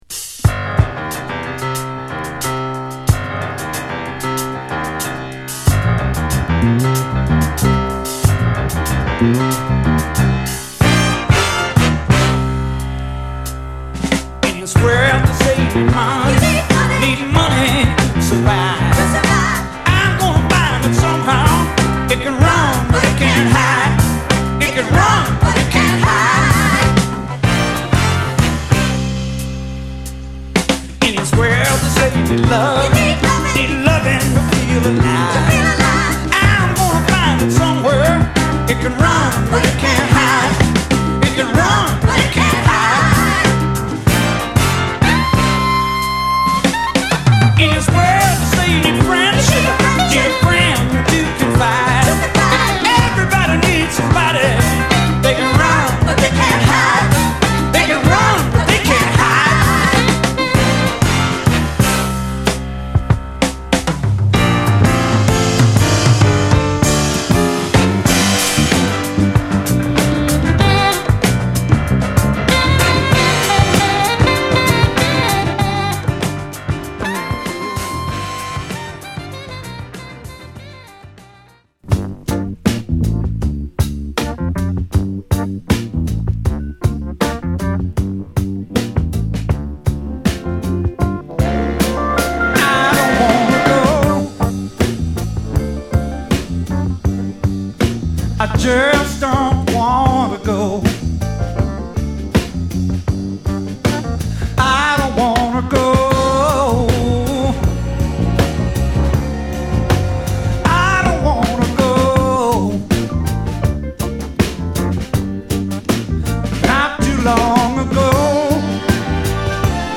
カッコ良いファンキーブルースを収録！